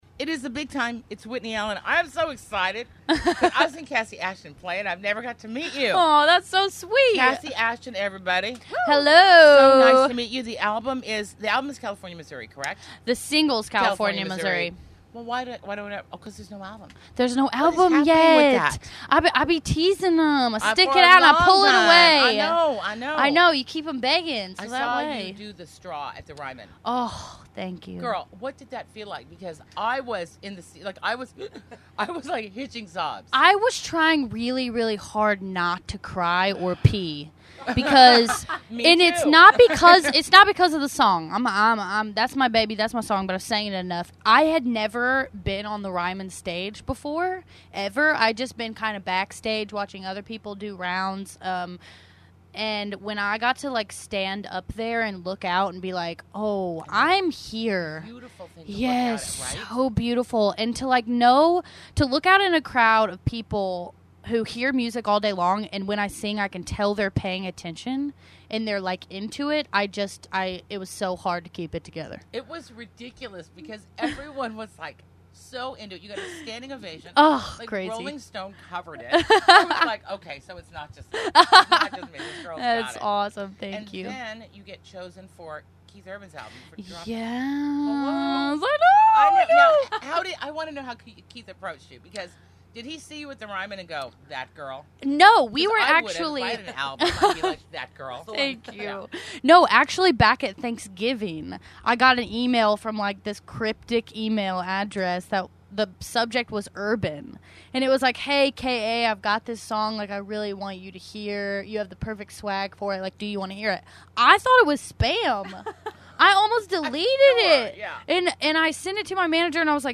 Kassi Ashton Interview From The Stagecoach Music Festival!
Big News / Interviews Kassi Ashton Interview From The Stagecoach Music Festival!